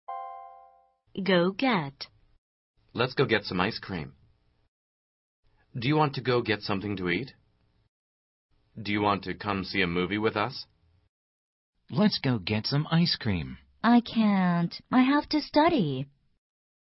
通过生动的对话说明短语的实际表达用法，8000多句最实用的经典表达，保证让你讲出一口流利又通顺的英语，和老外聊天时再也不用担心自己的英语错误百出了！